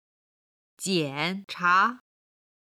今日の振り返り！中国語発声
jiancha.mp3